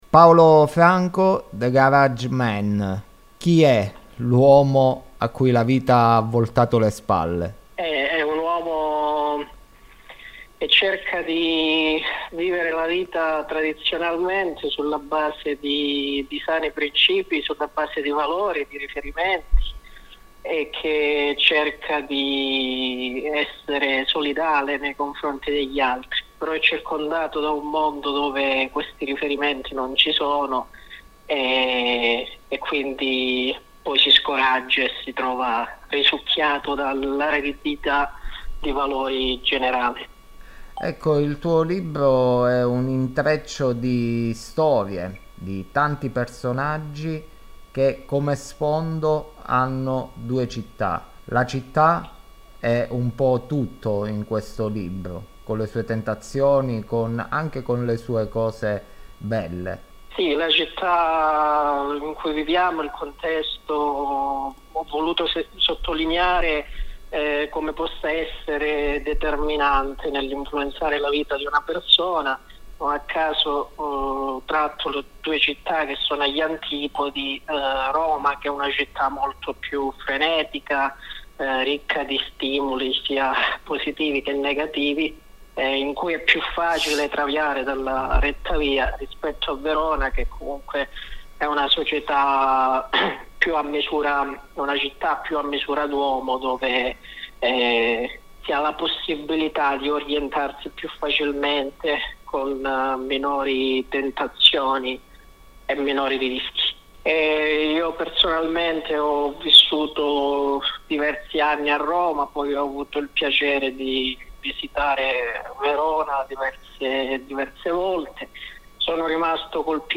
La nostra intervista